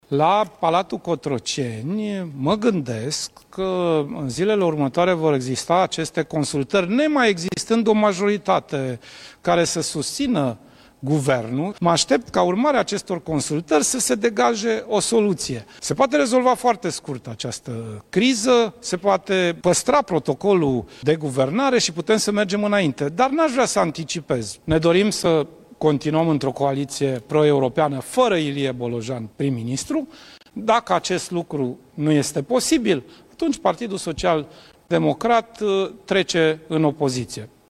Liderul PSD, Sorin Grindeanu: „Ne dorim să continuăm într-o coaliție proeuropeană”
Într-o intervenție la Antena 1, Sorin Grindeanu spune că miniștrii PSD se retrag din Guvern, iar președintele Nicușor Dan ar trebui să organizeze consultări pentru o viitoare guvernare.